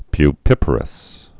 (py-pĭpər-əs)